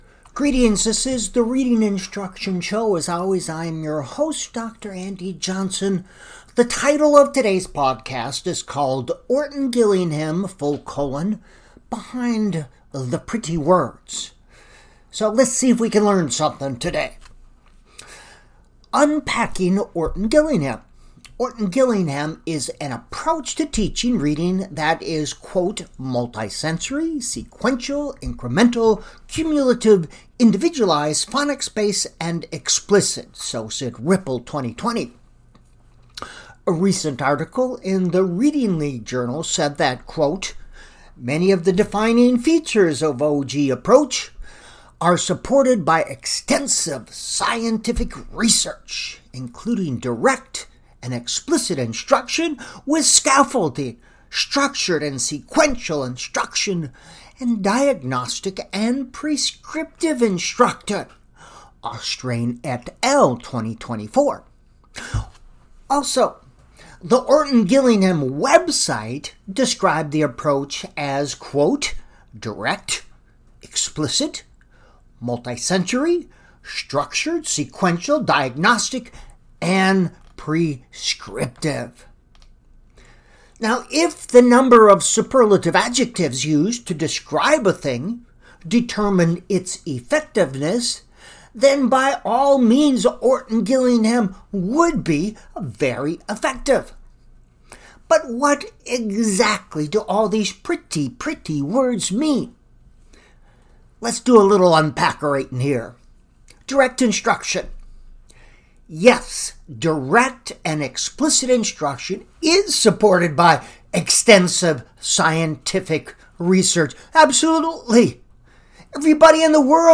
This is an interview with a Minnesota reading Professor. Ideology has replaced science when it comes to reading instruction in Minnesota.